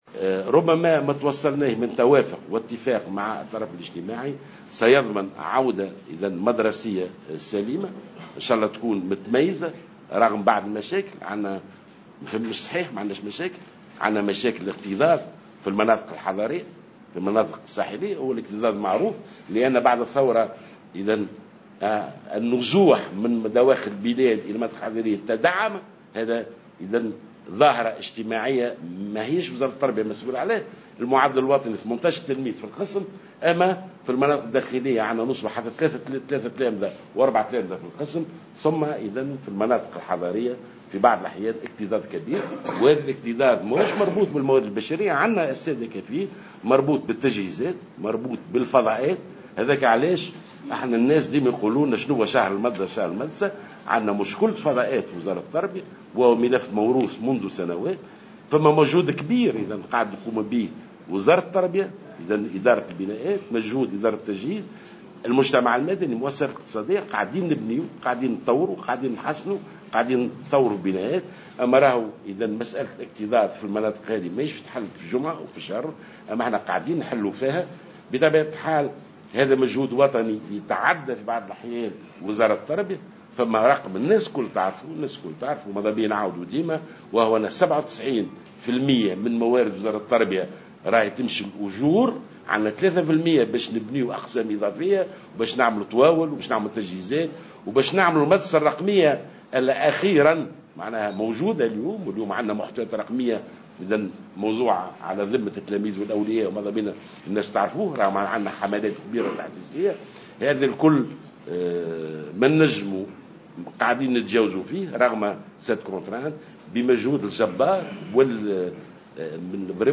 وأضاف جلول خلال ندوة صحفية انعقدت اليوم حول الاستعدادات للعودة المدرسية أن عديد المدارس تعاني من الاكتظاظ خاصة في المناطق الساحلية، مشيرا إلى أن مشكل الاكتظاظ مرتبط بنقص الفضاءات والتجهيزات وليس بالموارد البشرية، وفق تعبيره.